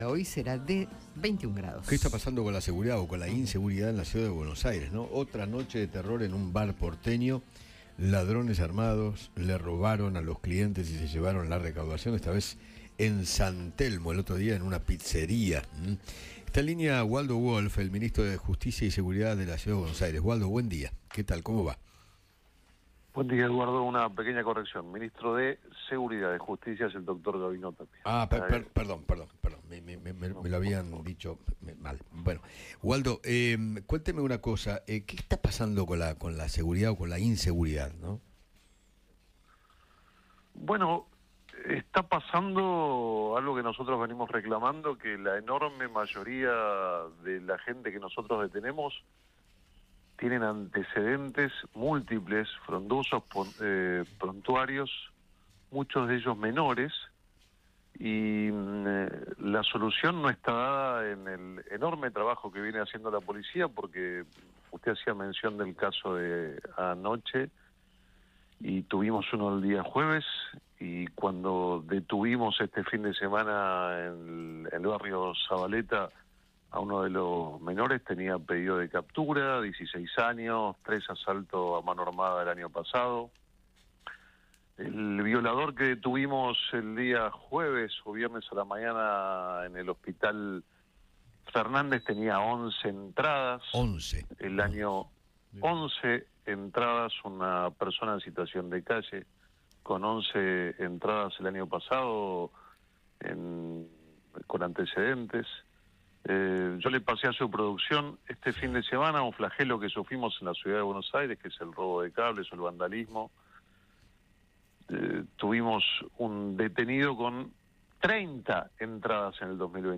Waldo Wolff, ministro de Seguridad porteño, conversó con Eduardo Feinmann sobre el robo piraña que sufrió una cafetería de San Telmo durante el domingo a la madrugada.